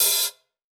PHH OPEN.wav